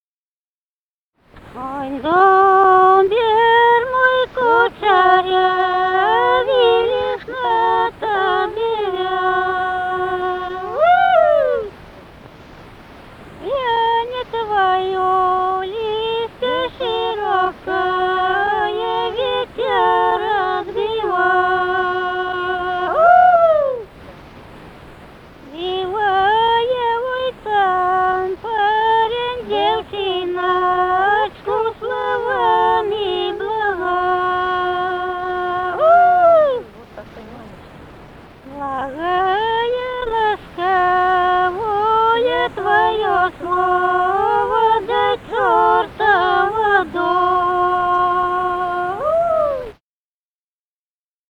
Музыкальный фольклор Климовского района 010. «Ой, дубер мой кучерявый» (весенняя).